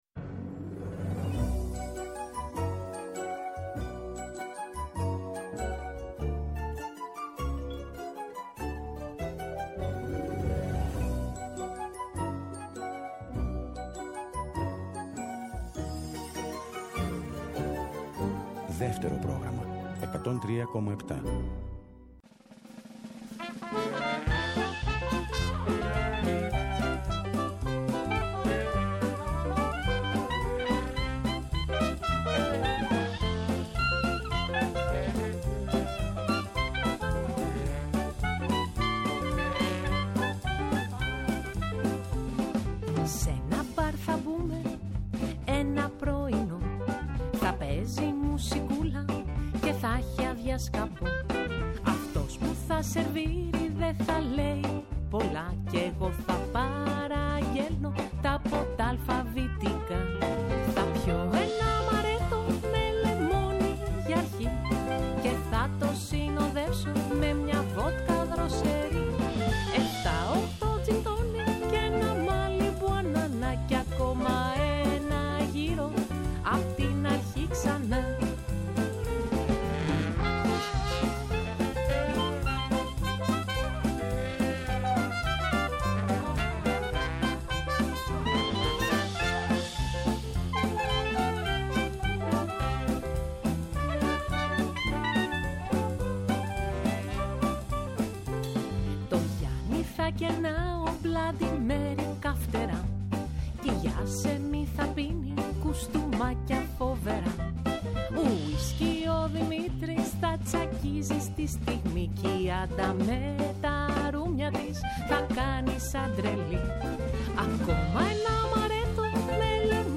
έχει καλεσμένη (τηλεφωνικά) στην εκπομπή της “Πρωινό Ραντεβού” την Μαρία Κηλαηδόνη.
Συνεντεύξεις